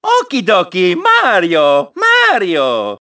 One of Mario's voice clips in Mario Kart 7